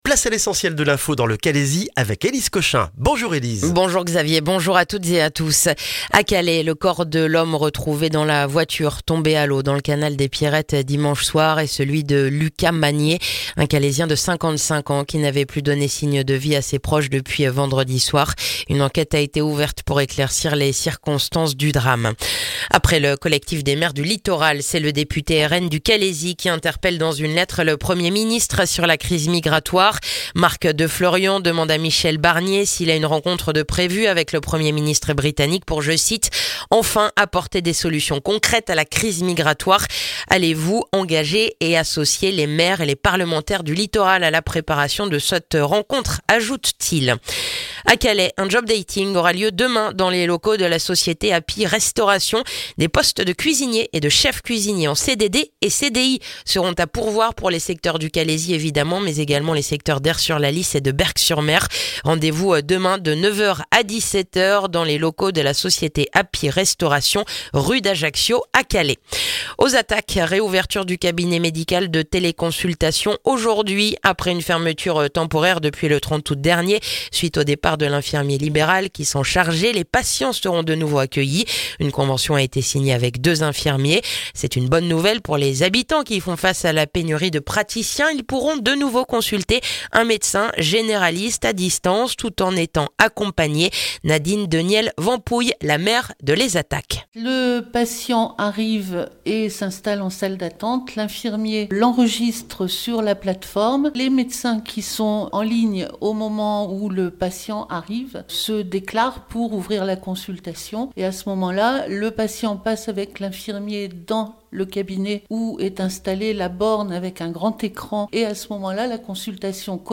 Le journal du mardi 15 octobre dans le calaisis